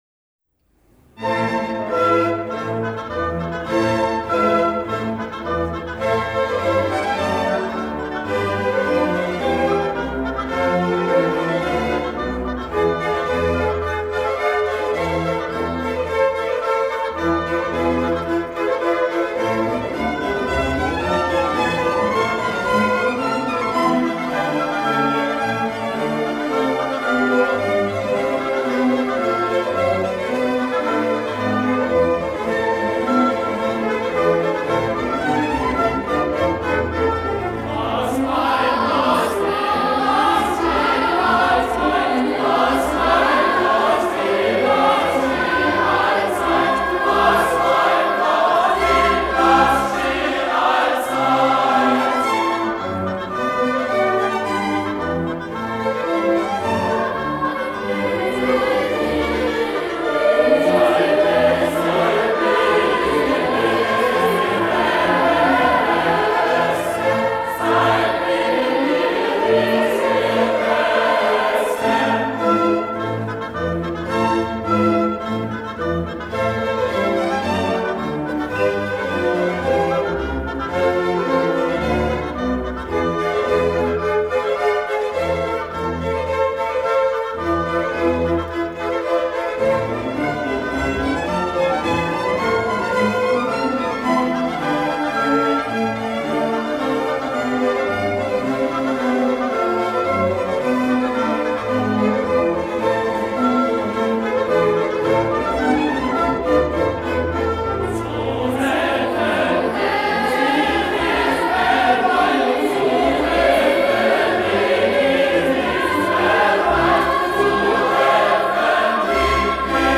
These are performances historically and musically unique, sung with power and passion.
THOMANERCHOR & Gewandhaus Orchestra – Günther RAMIN – Vol.2